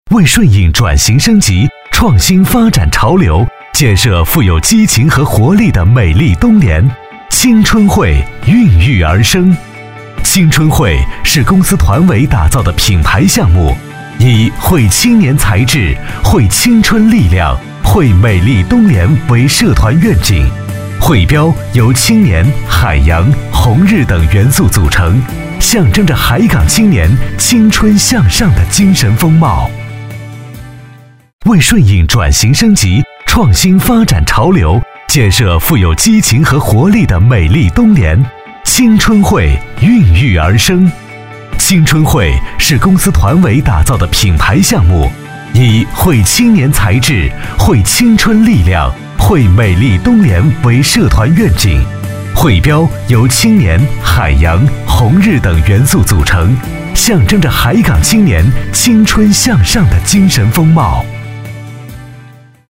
国语青年大气浑厚磁性 、沉稳 、科技感 、积极向上 、时尚活力 、男广告 、400元/条男S347 国语 男声 公益广告-不忘初心-轻松自然 大气浑厚磁性|沉稳|科技感|积极向上|时尚活力